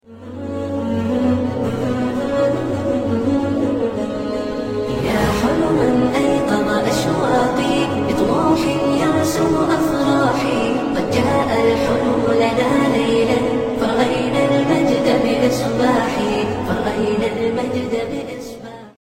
الله احلا انشوده لدي ❤❤❤